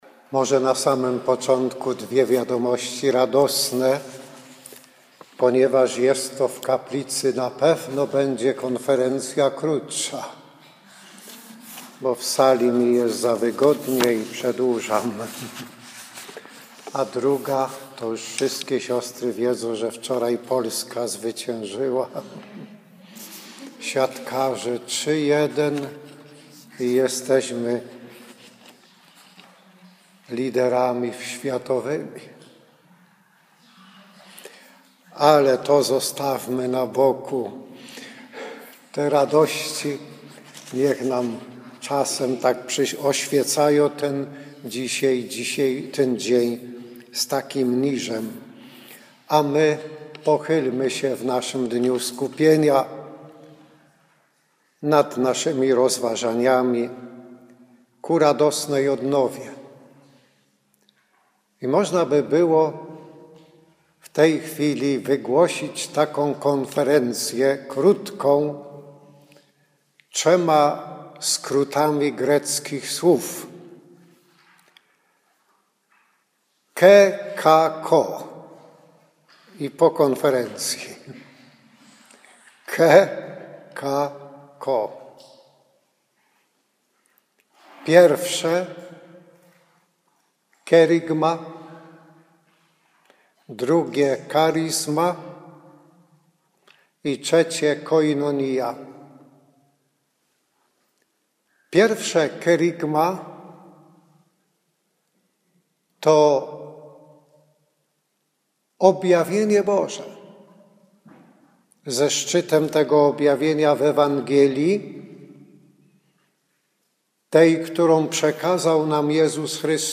Przygotowując się do jubileuszowych uroczystości przeżywałyśmy w poniedziałek, 22 września dzień skupienia